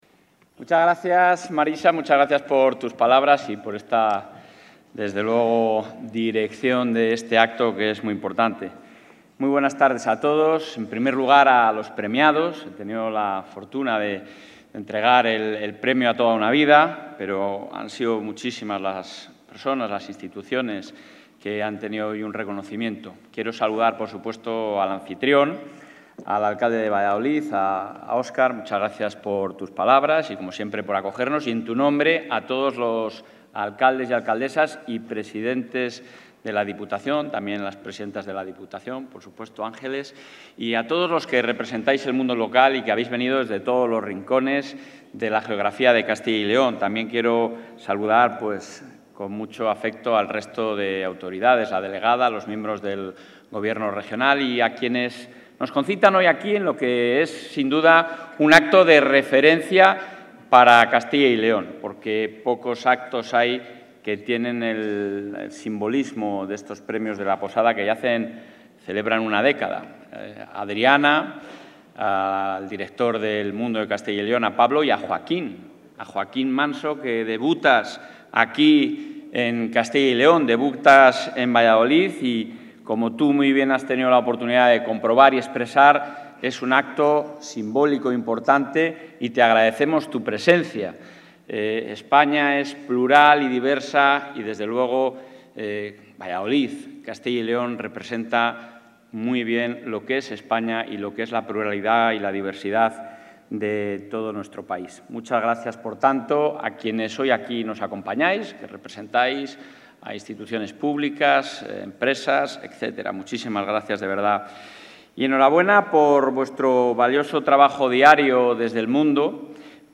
En la Gala de los X Premios de La Posada, organizada por El Mundo Castilla y León, el presidente autonómico, Alfonso Fernández...
Intervención del presidente de la Junta.